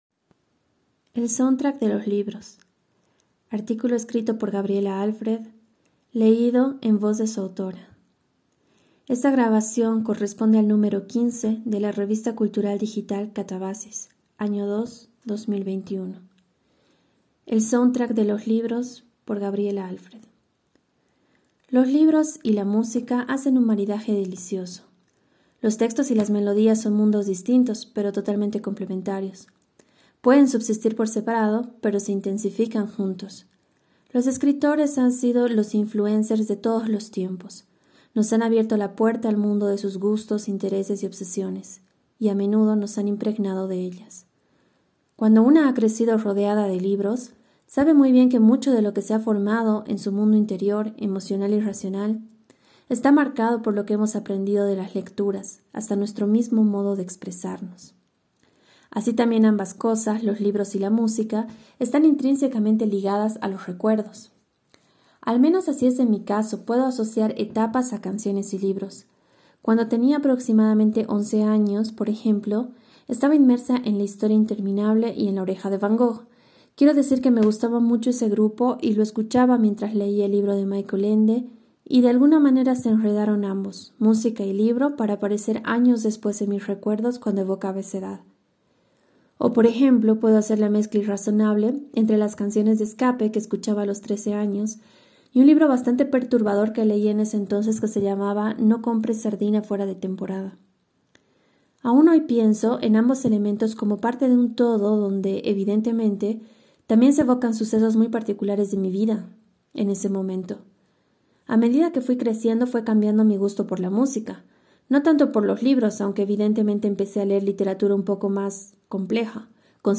En voz de su autora